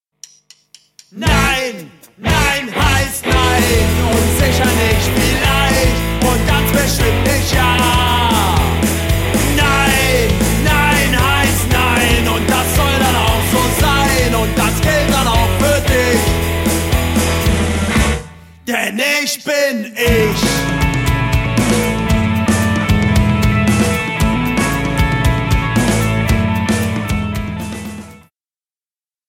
Rock 'n' Roll im Kindergarten!